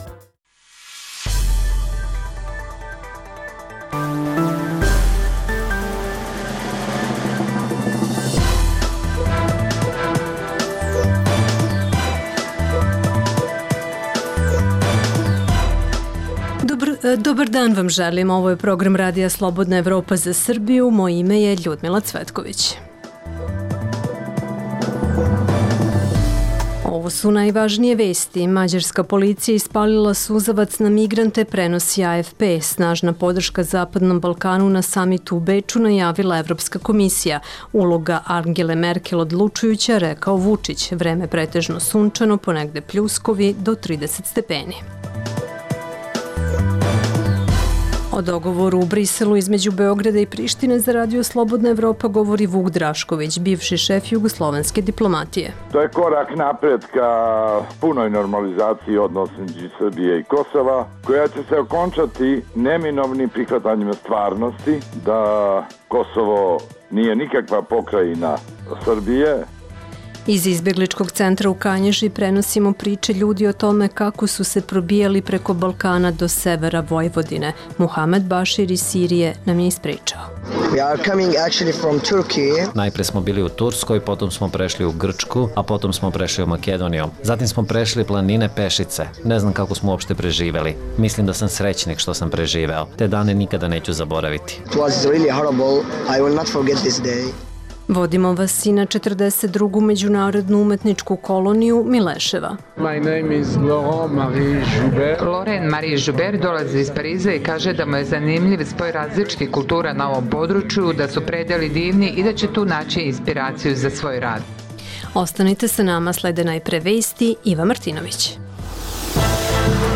- U Beču počela dvodnevna konferencija o Zapadnom Balkanu. Izveštavaju reporteri RSE.